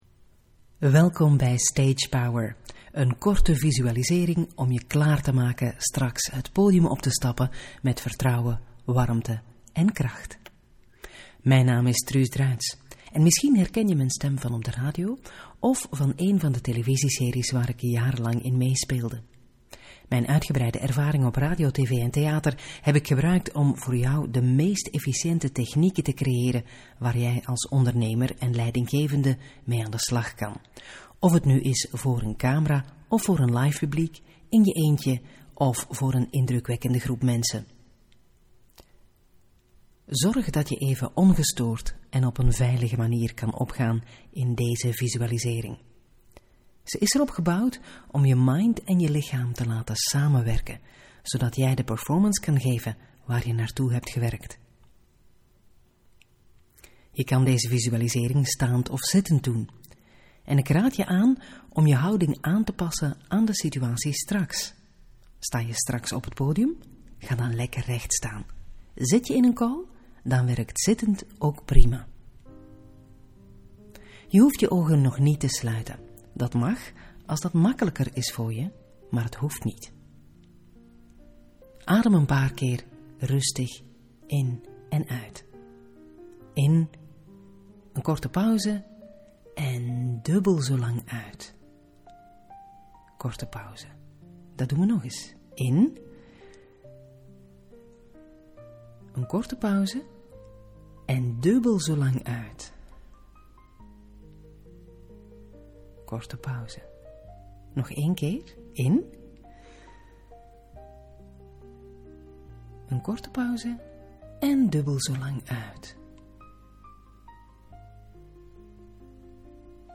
DAG 3: Even een moment voor jezelf Elke week mag je ook een meditatie van ons verwachten. De meditatie van deze week gaat over het podium